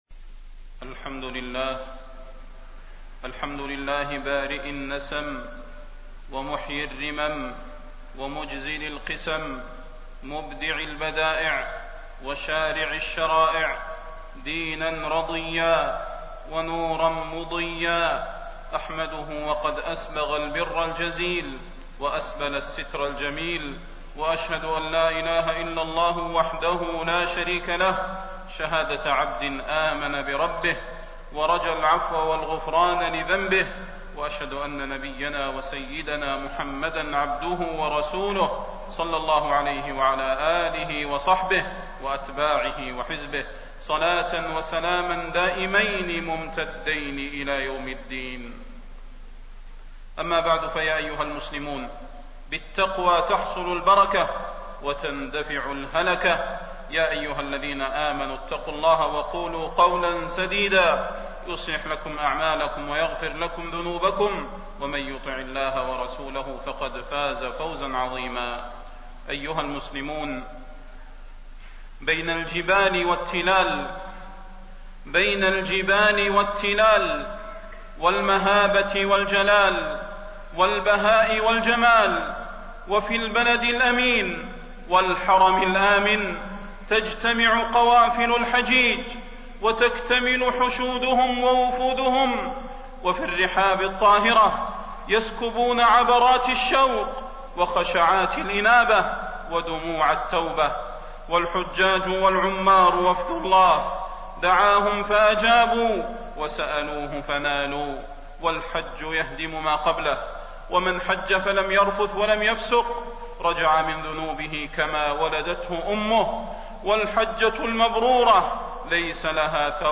تاريخ النشر ٨ ذو الحجة ١٤٣٢ هـ المكان: المسجد النبوي الشيخ: فضيلة الشيخ د. صلاح بن محمد البدير فضيلة الشيخ د. صلاح بن محمد البدير يوم عرفة يوم عظيم